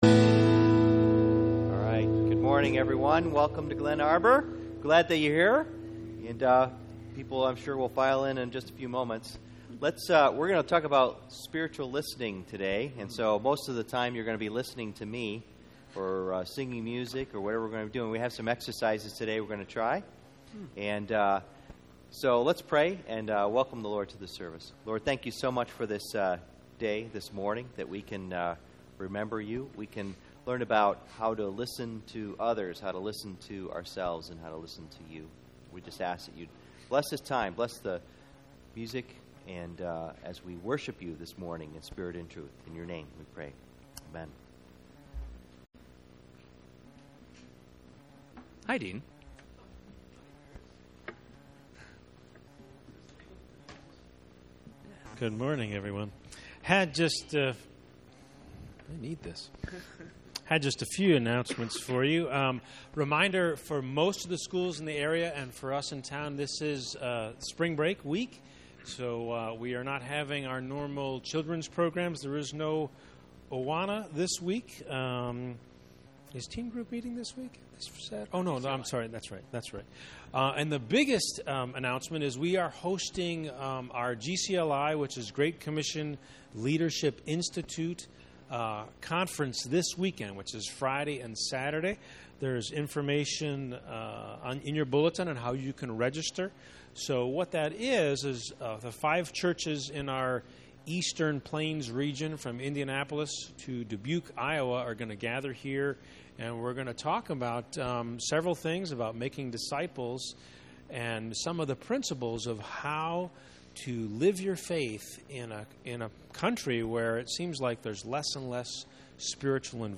Spiritual Communication Service Type: Sunday Morning %todo_render% « Spiritual Identity